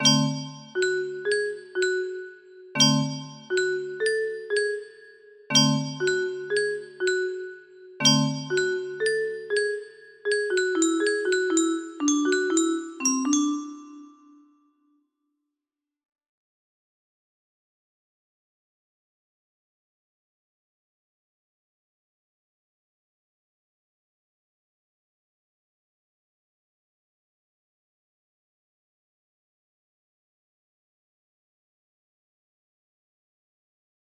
Music Box thingy music box melody